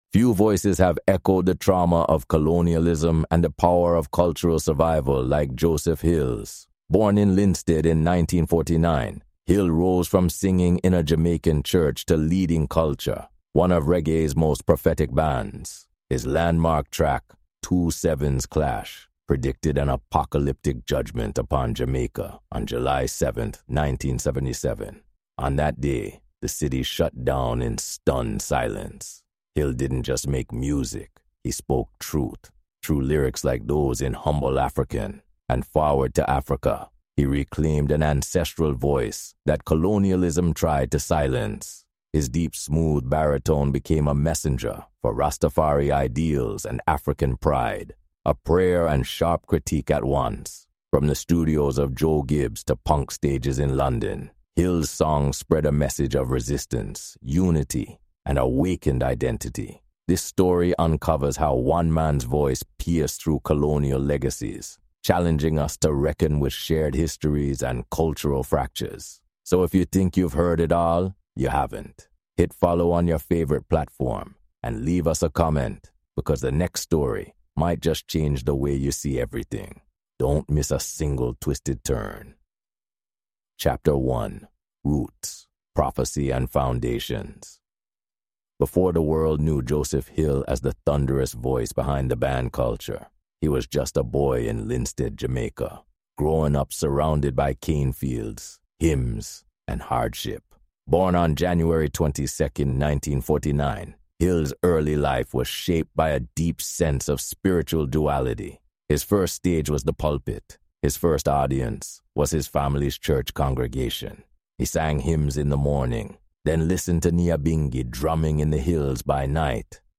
Through expert commentary on cultural resilience, Maroon communities, and the enduring spirit of island life, this episode asks: Can art erase colonial amnesia?